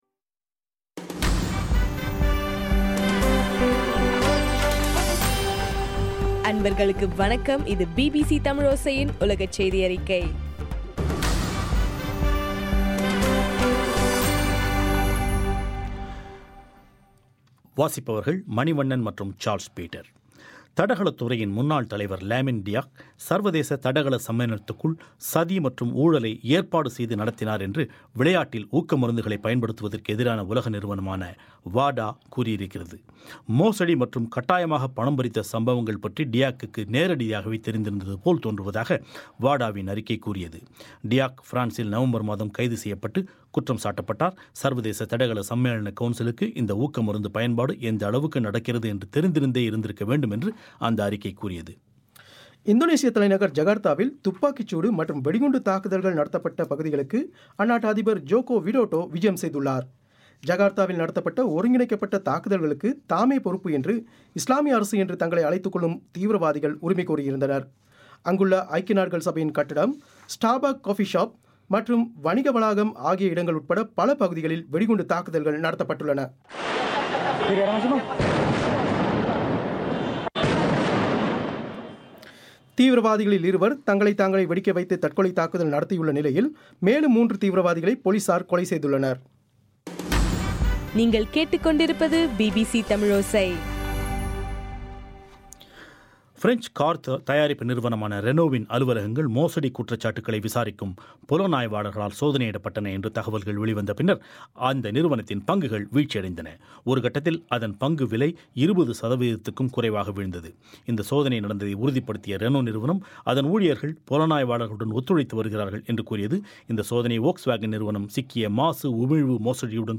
ஜனவரி 14 பிபிசியின் உலகச் செய்திகள்